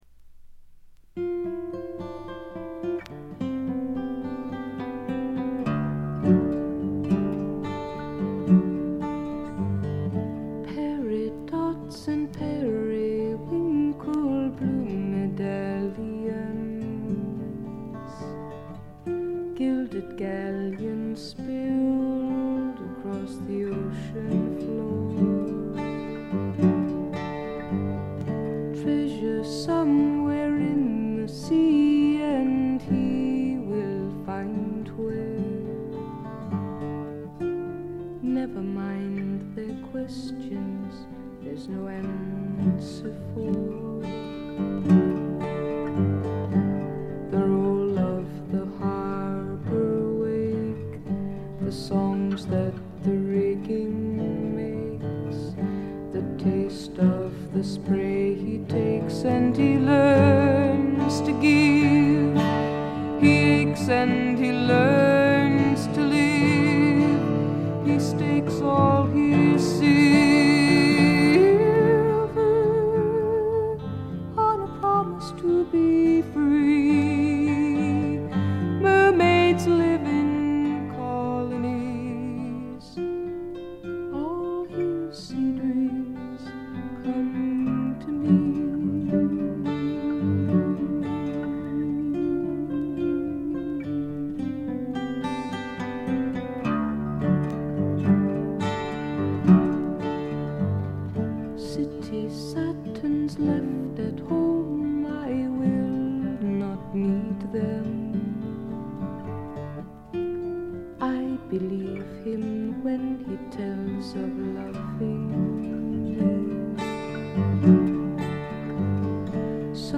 プツ音が2箇所ほど出たかな？」という程度でごくわずかなノイズ感のみ。
至上の美しさをたたえたサイケ・フォーク、アシッド・フォークの超絶名盤という見方もできます。
試聴曲は現品からの取り込み音源です。
guitar, piano, vocals